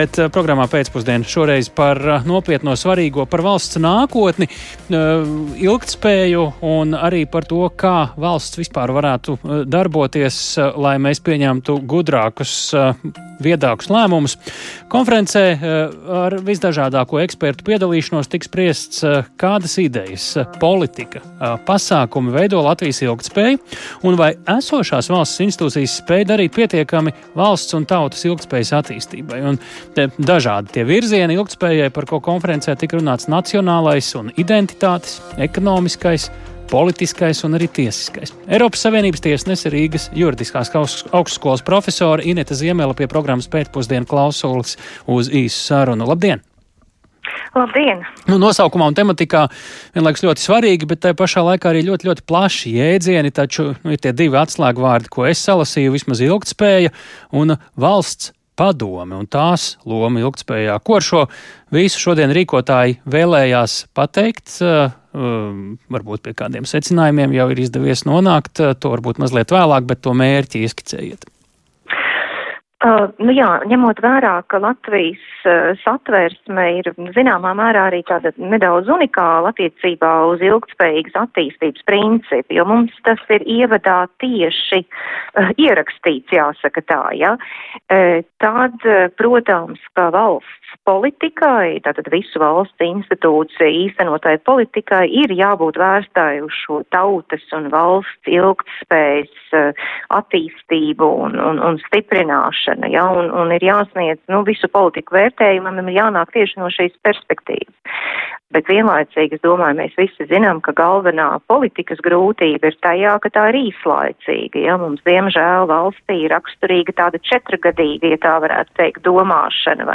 Kā aizritēja konference, stāsta Eiropas Savienības Tiesas tiesnese, Rīgas Juridiskās augstskolas profesore Ineta Ziemele (attēlā).